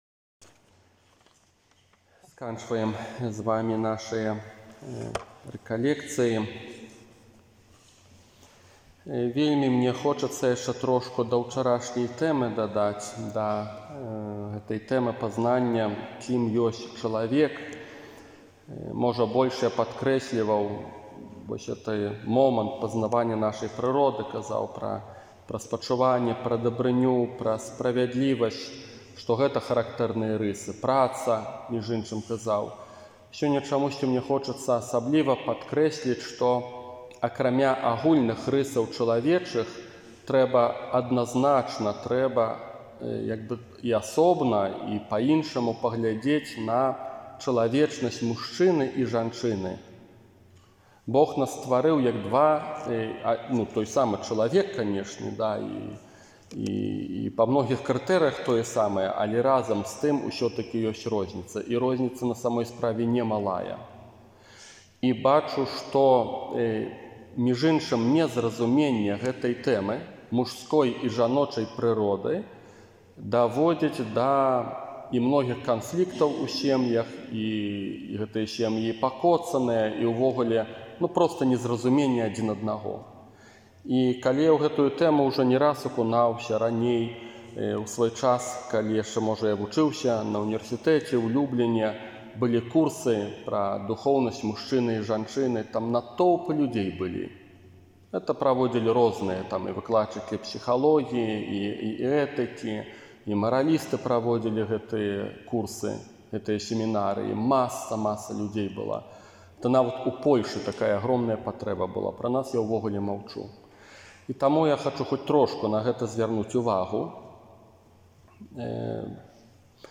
Для тых, хто не меў магчымасці ўдзельнічаць у Адвэнтавых рэкалекцыях, а таксама для ўсіх зацікаўленых, прапаную рэкалекцыі, якія адбыліся ў Вялікай Бераставіцы